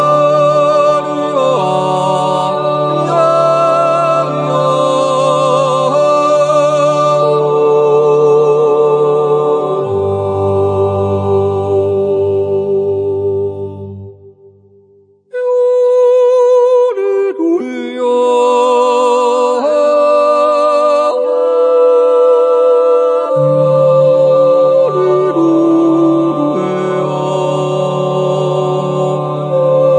Jodellieder, Naturjodel, Ratzliedli